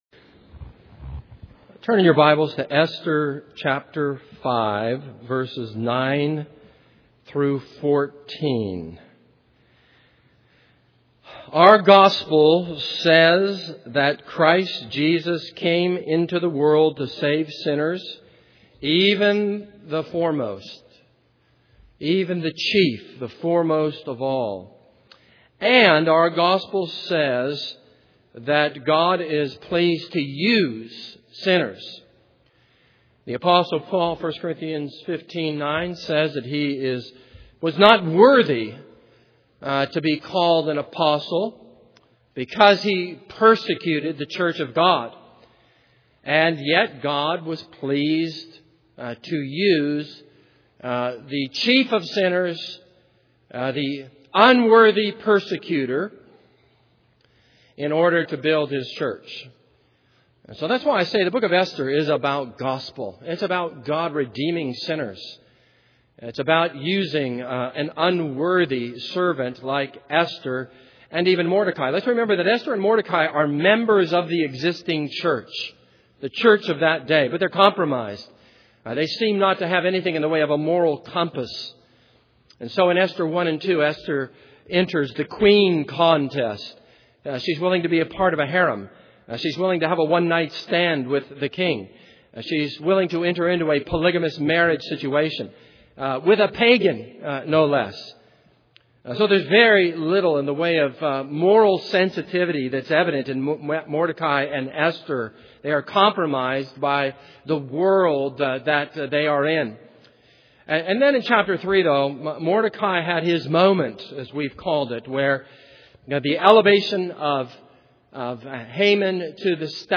This is a sermon on Esther 5:9-14.